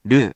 If you press the 「▶」button on the virtual sound player, QUIZBO™ will read the random hiragana to you.
In romaji, 「る」 is transliterated as 「ru」which sounds sort of like 「loo」